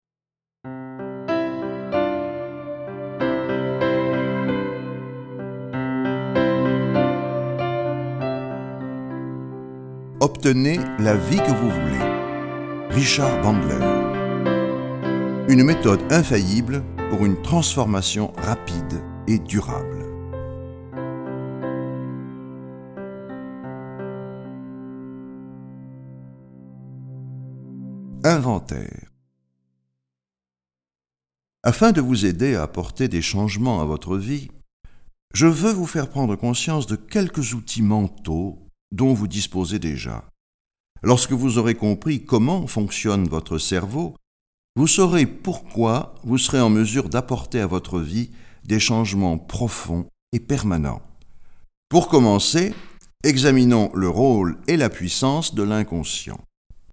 Click for an excerpt - Obtenez la vie que vous voulez de Richard Bandler